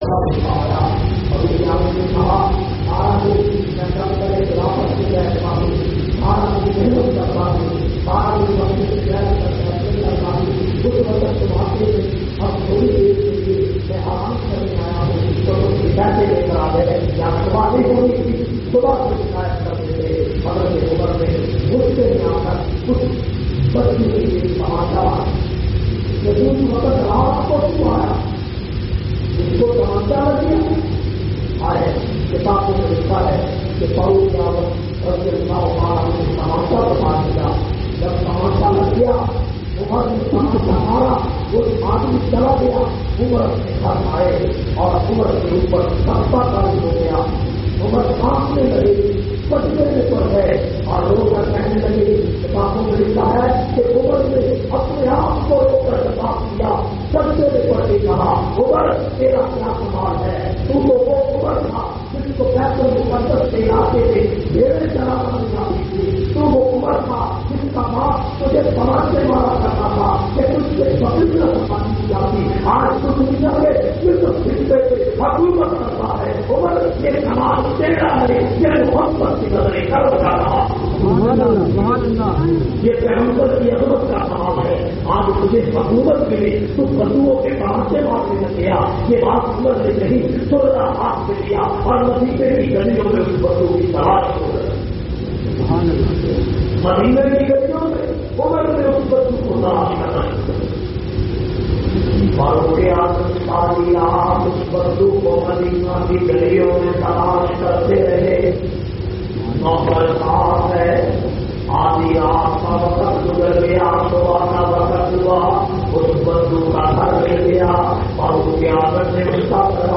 469- Hazrat Umar Farooq khutba jumma Jamia Masjid Muhammadia Samandri Faisalabad.mp3